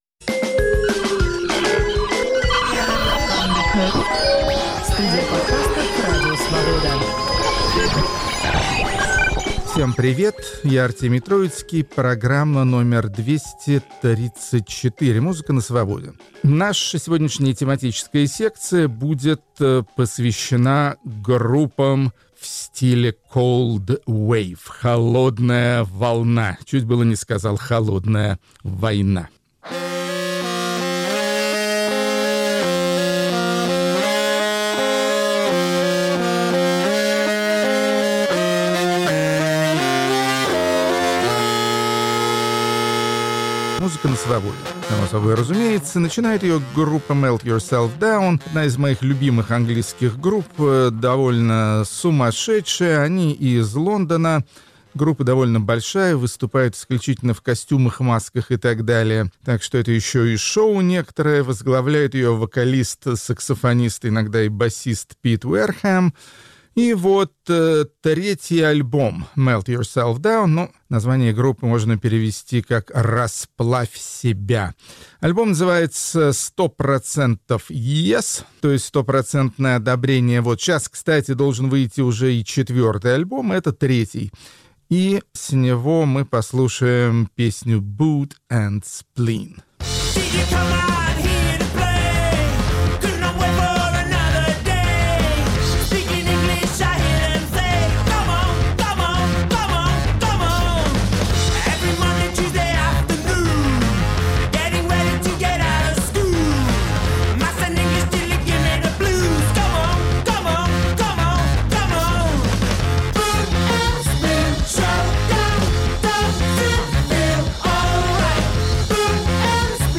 Артемий Троицкий сдвигается к электро
Хедлайнеры двести тридцать четвертого выпуска подкаста и радиопрограммы "Музыка на Свободе" – исполнители разных национальных школ, работающие в обретающем новую популярность жанре cold wave.